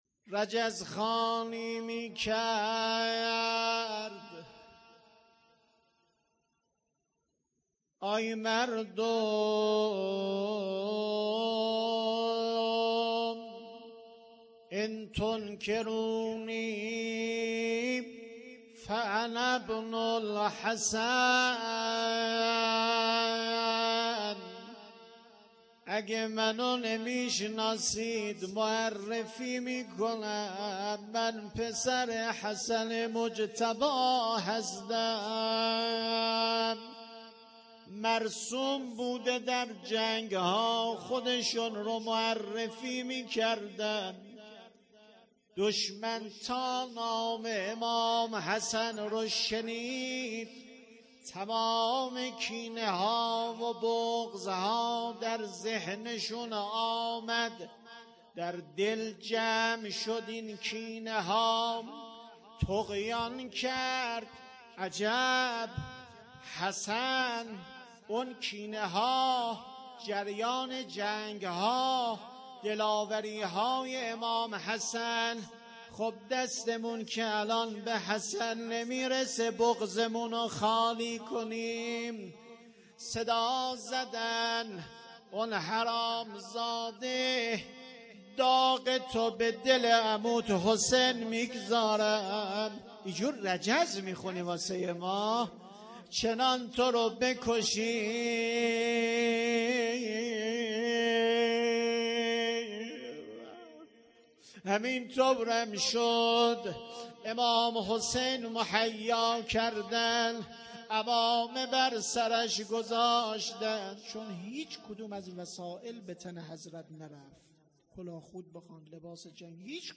روضه خوانی
شب ششم محرم ۱۴۰۱